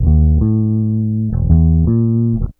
BASS 33.wav